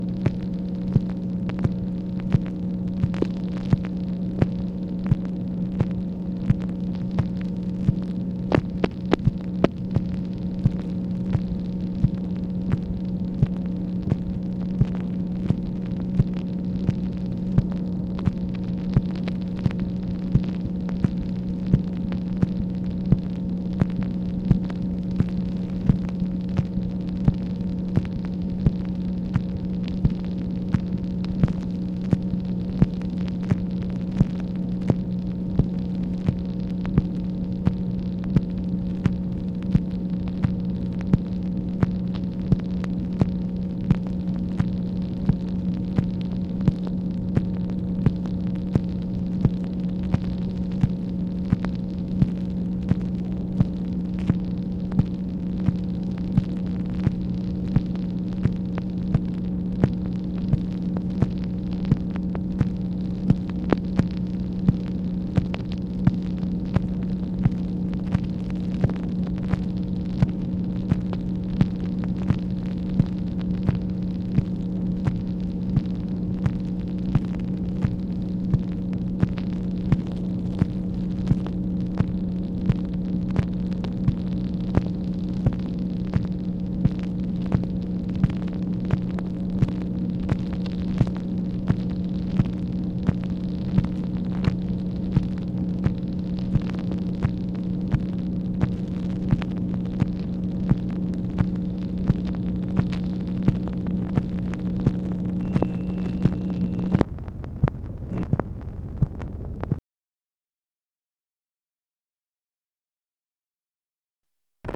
MACHINE NOISE, May 5, 1965
Secret White House Tapes